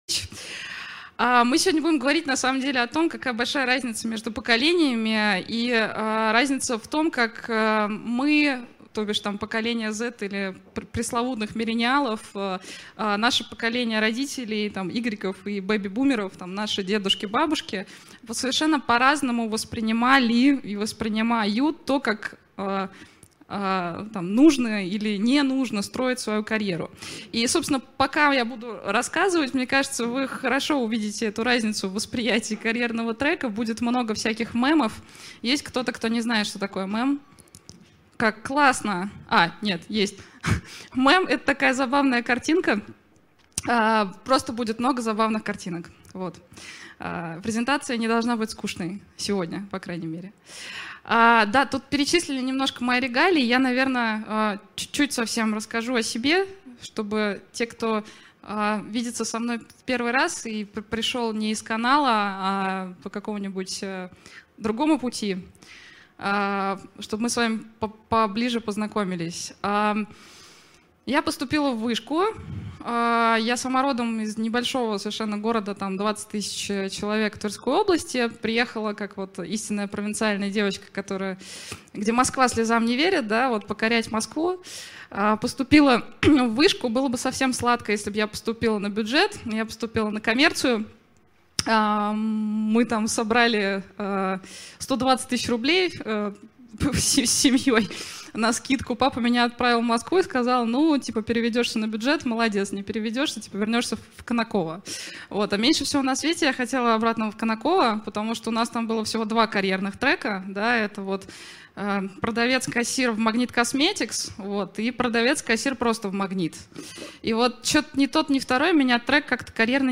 Аудиокнига Пути карьерные неисповедимы, или К каким профессиям стремится молодое поколение | Библиотека аудиокниг